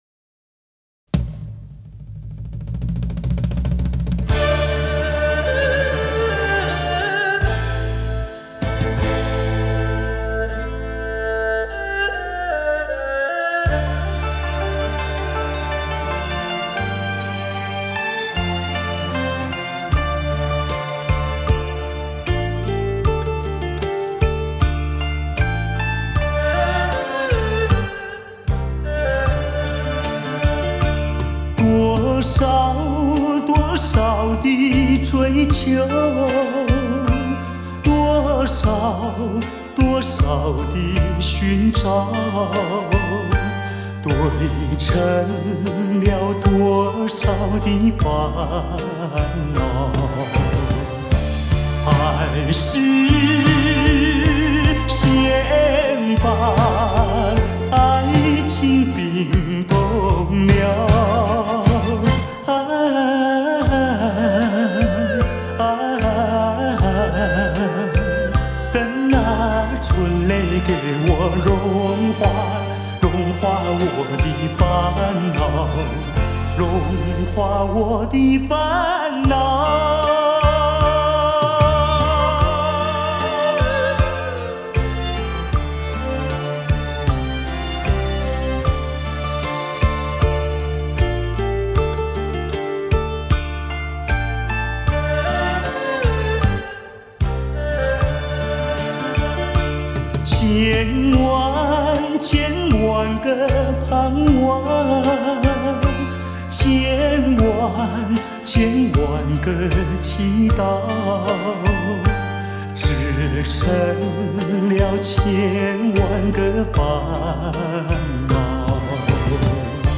样本格式    : 44.100 Hz;16 Bit;立体声